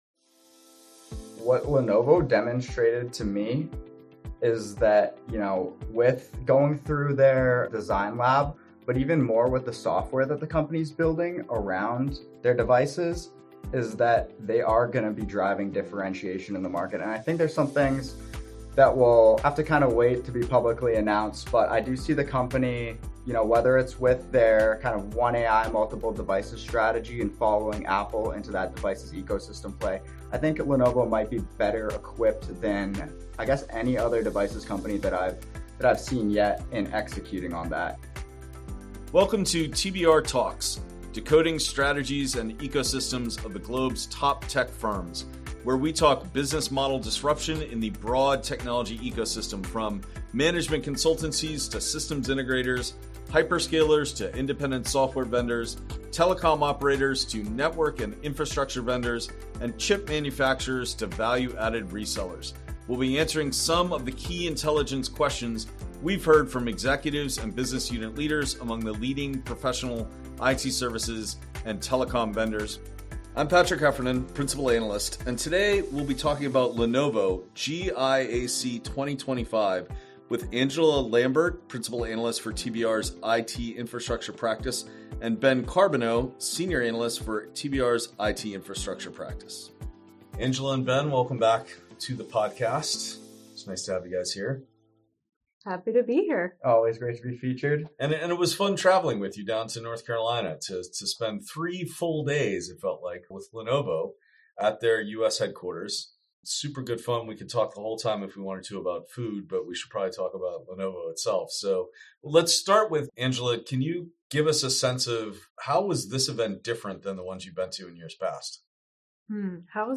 The pair discusses the event’s AI strategy announcement, how this year’s GIAC differed from past years and where Lenovo is differentiating itself from peers